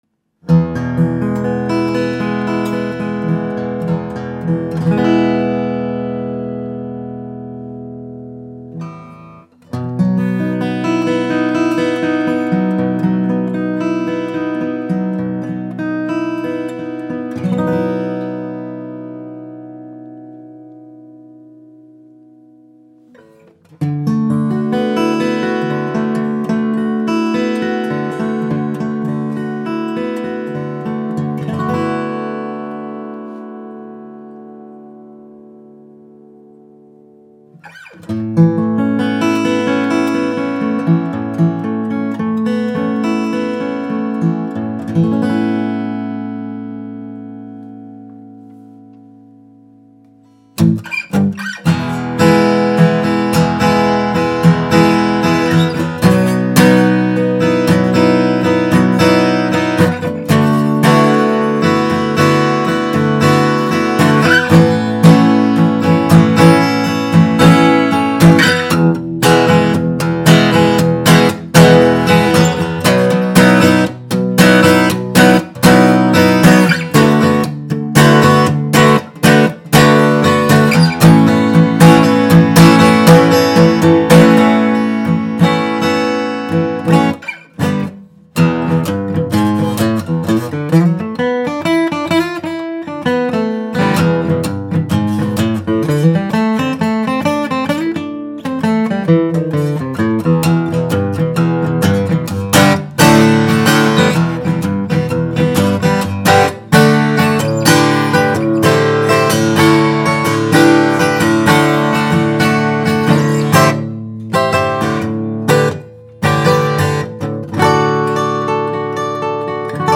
Martin D-180 Anniversary Edition #4 with premium Adirondack Red Spruce and Madagascar Rosewood abundantly inlaid with Pearl.
Superb looks and even more important: from a tonal point of view, this Martin is amongst the best of the best.
This variation of scalloping helps the tone and responsiveness, by letting the top vibrate more freely.